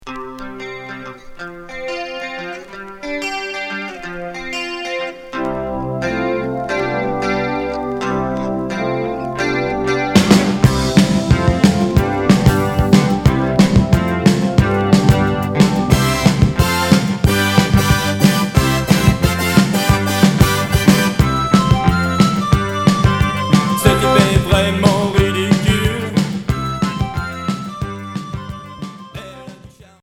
New wave Unique 45t retour à l'accueil